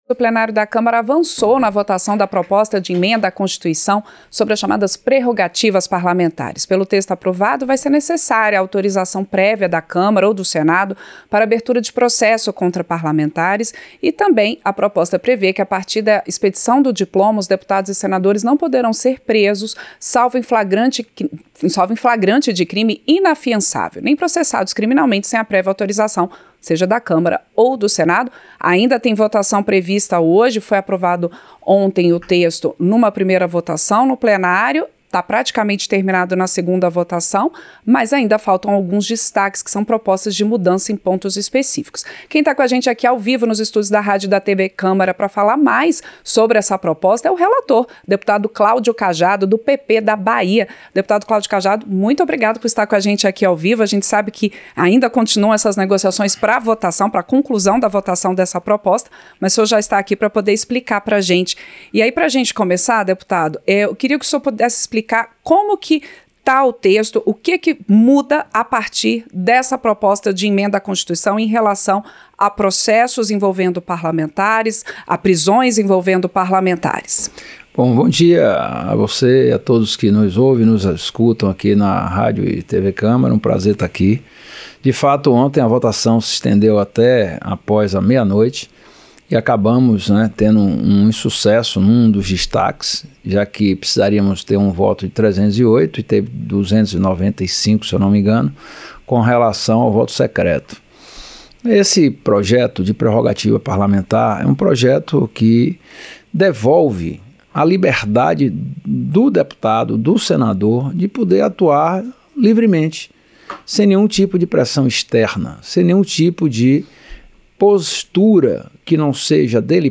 O relator da chamada PEC das Prerrogativas (PEC 3/2021), deputado Claudio Cajado (PP-BA), disse que o texto não é privilégio ou salvo-conduto para cometimento de crime. Ele falou ao vivo ao Painel Eletrônico desta quarta-feira (17), na manhã seguinte à votação da proposta de emenda à Constituição pelo Plenário da Câmara dos Deputados.
Entrevista - Dep.